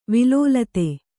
♪ vilōlate